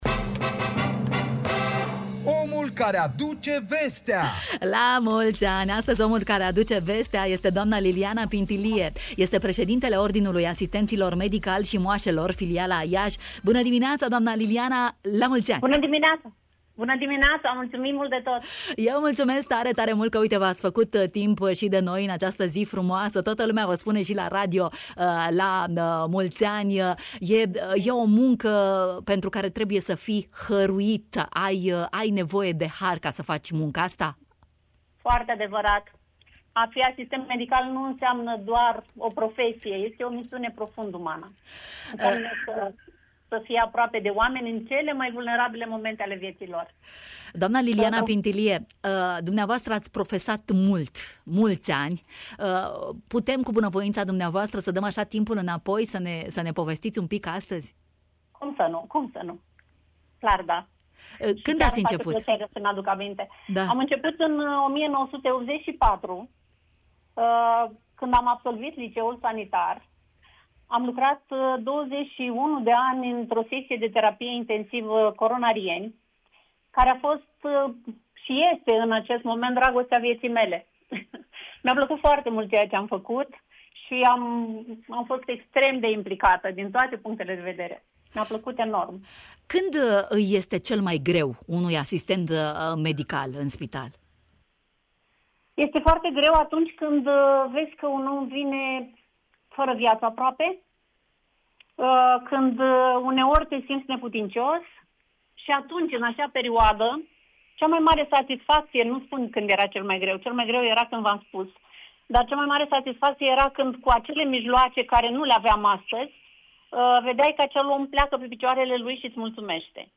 în direct la Radio Iași